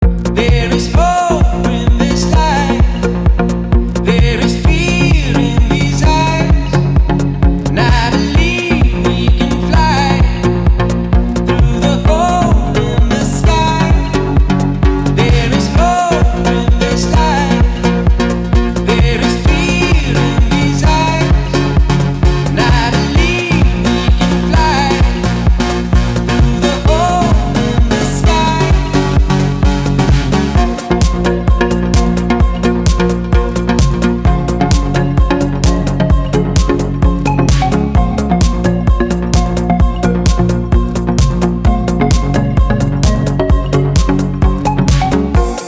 • Качество: 193, Stereo
house